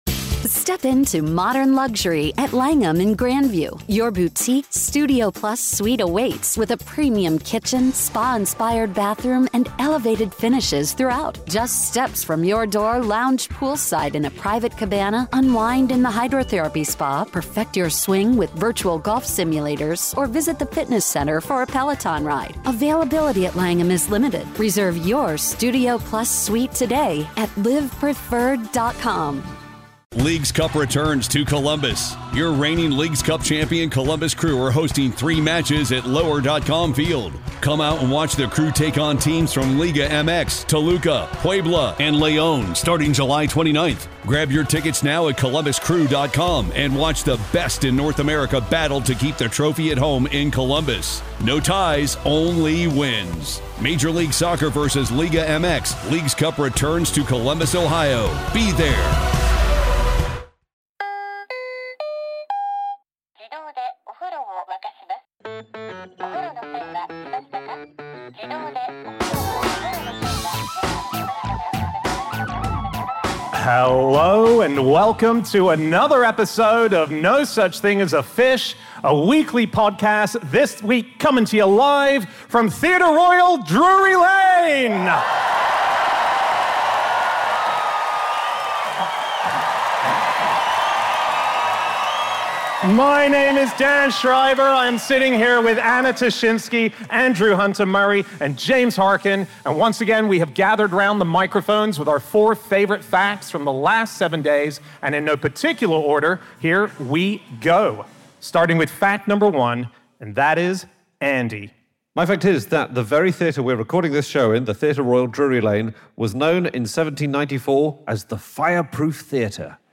Live from the Theatre Royal, Drury Lane